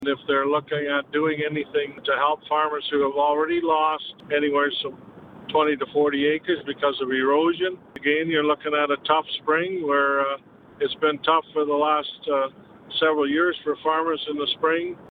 Mayor Jim Harrison tells Quinte News he intends to ask if there will be any help for them.